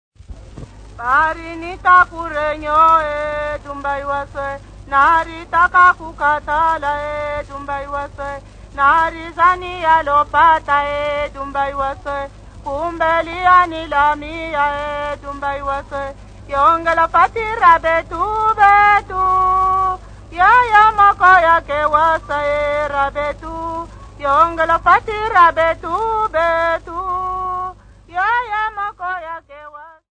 Folk Music
Field recordings
Africa, Sub-Saharan
sound recording-musical
Indigenous music